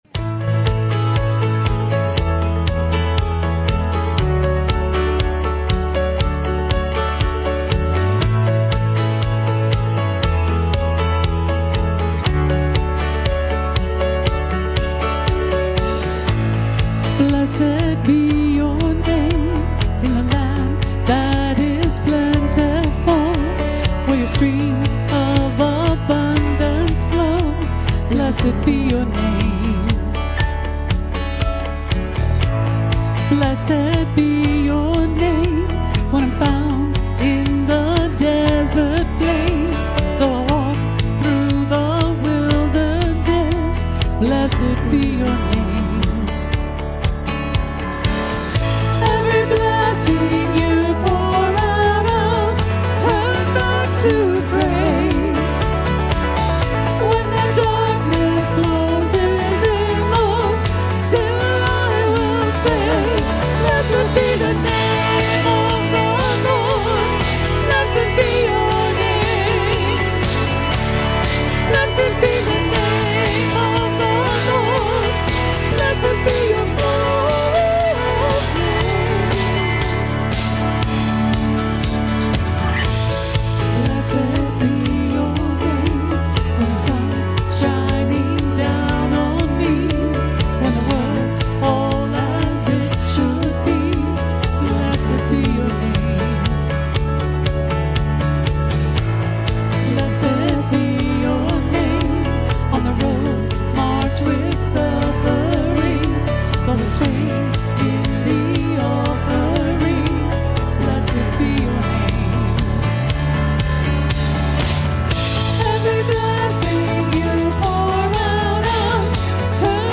Praise Team Audio